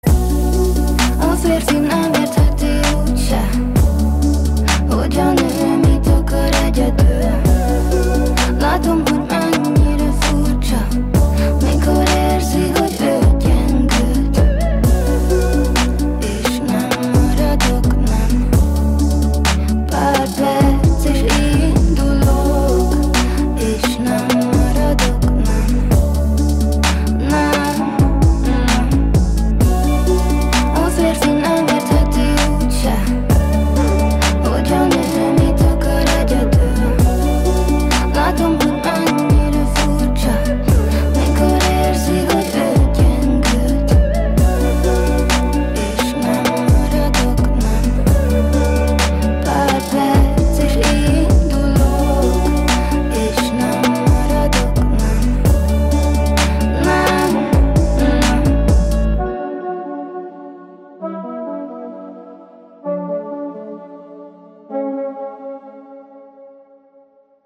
• Качество: 157, Stereo
поп
мелодичные
спокойные
красивый женский вокал